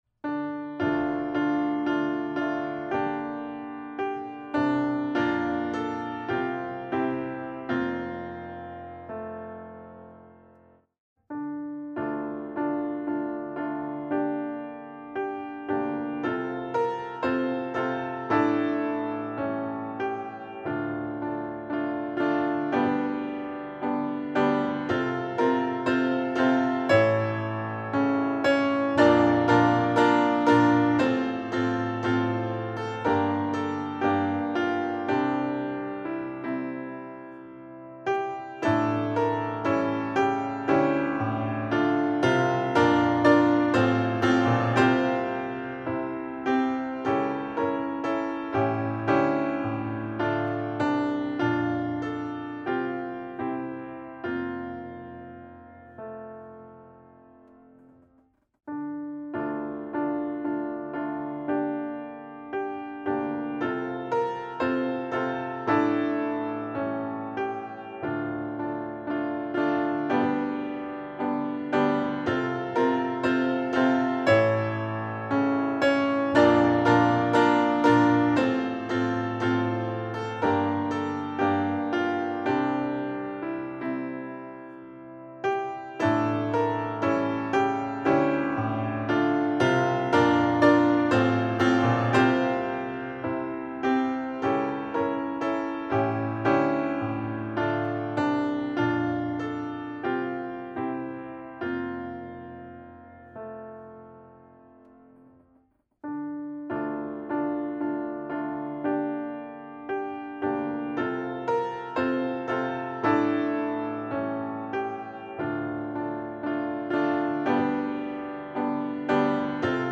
To See Thy Face - Performance Bosendorfer.mp3